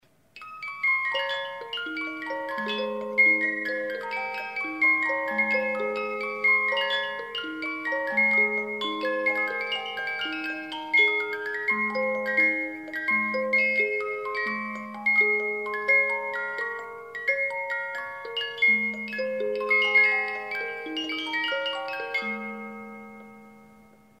Классическая музыка
волшебной шкатулки